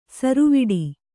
♪ saruviḍi